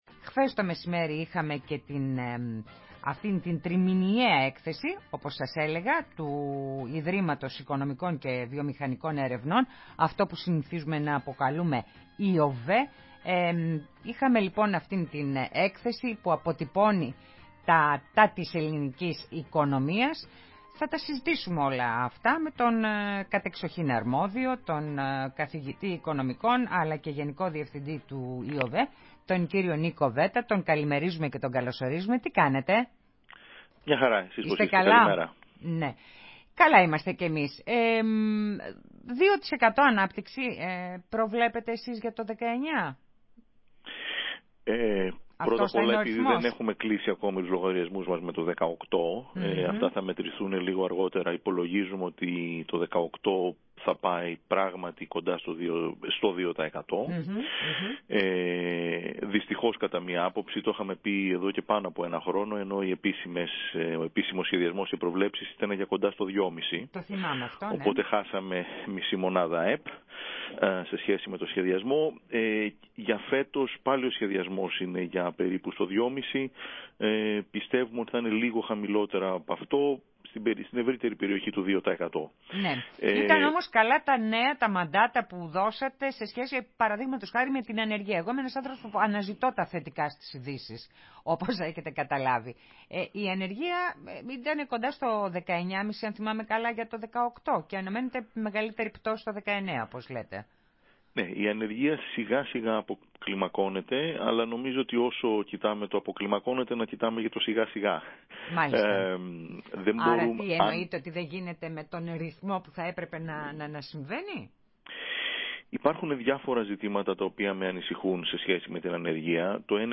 Συνέντευξη στο ραδιόφωνο της ΕΡΤ